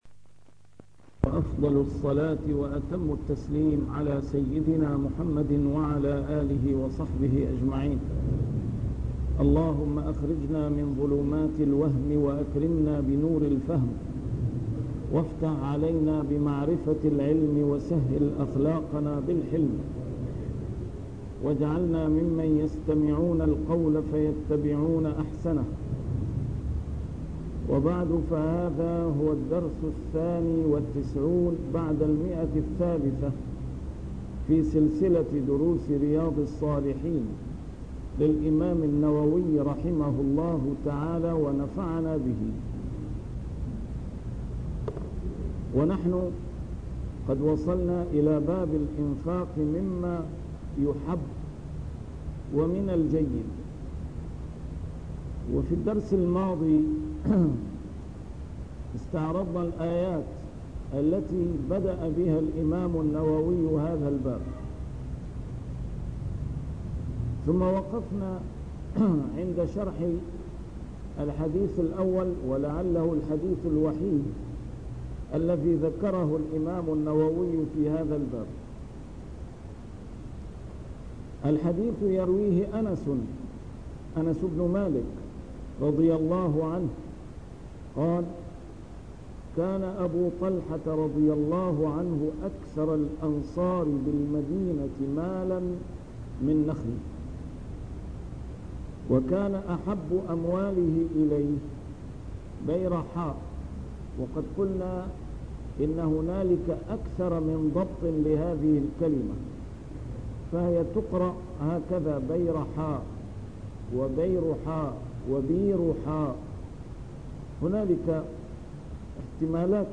A MARTYR SCHOLAR: IMAM MUHAMMAD SAEED RAMADAN AL-BOUTI - الدروس العلمية - شرح كتاب رياض الصالحين - 392- شرح رياض الصالحين: الإنفاق مما يحب